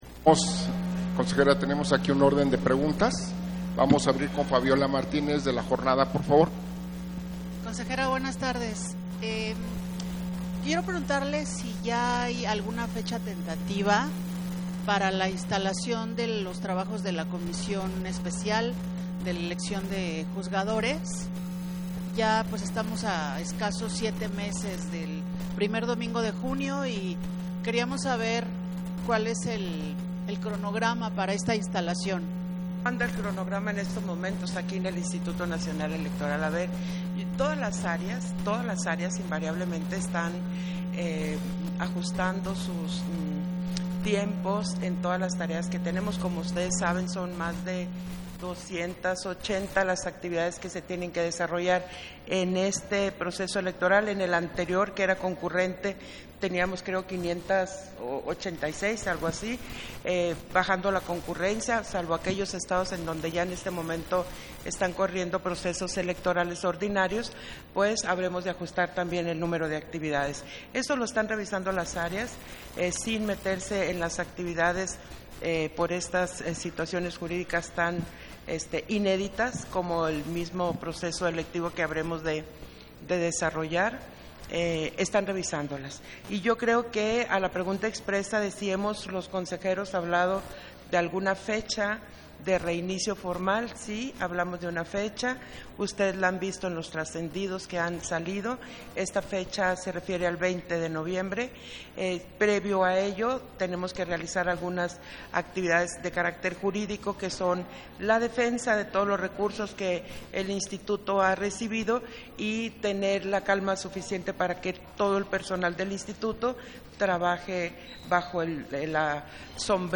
141124_AUDIO_CONFERENCIA-DE-PRENSA-CONSEJERA-PDTA.-TADDEI - Central Electoral